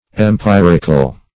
Search Result for " empyrical" : The Collaborative International Dictionary of English v.0.48: Empyrical \Em*pyr"ic*al\, a. [Gr.